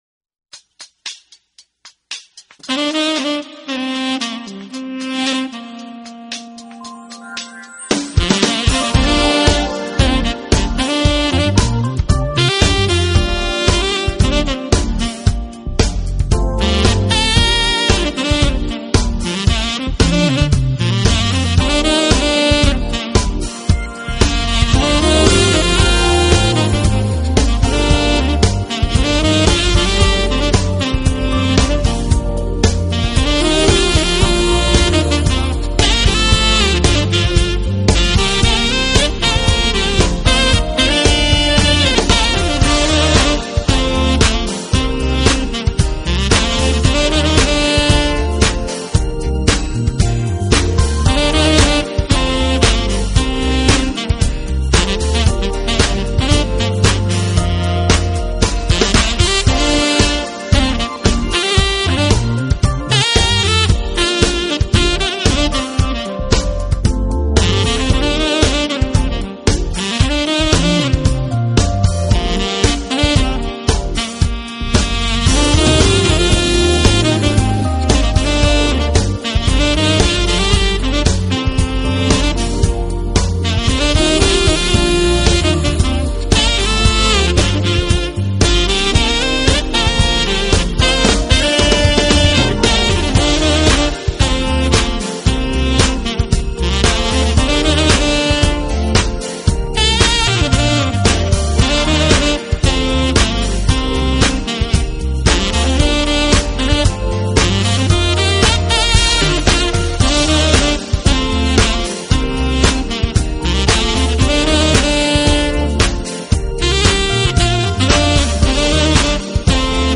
Genre: Jazz/Smooth Jazz